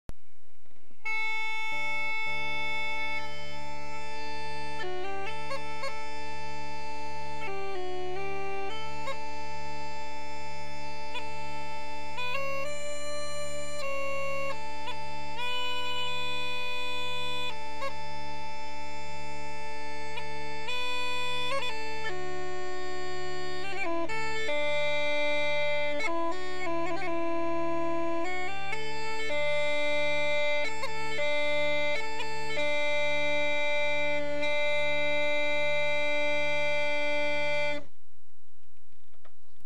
PipingUilleann Pipes
Some sample clips (.wma) of my piping
An Air (490k)